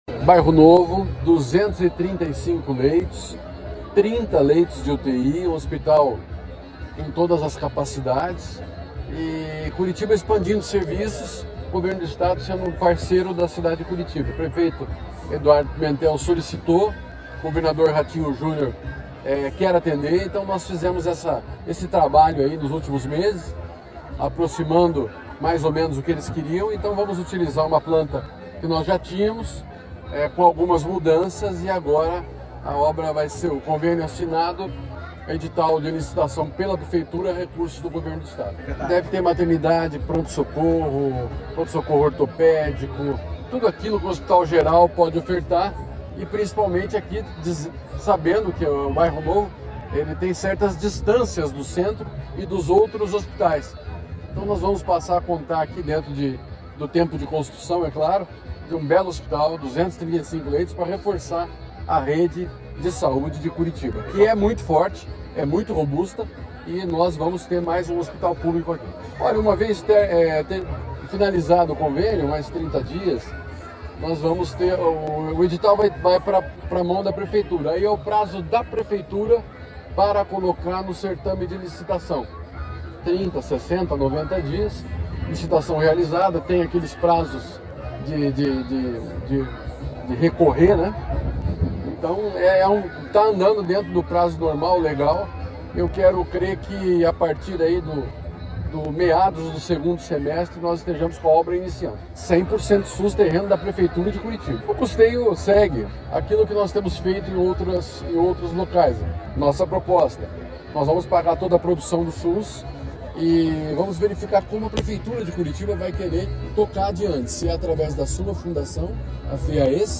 Sonora do secretário da Saúde, Beto Preto, sobre o anúncio do Hospital Bairro Novo, em Curitiba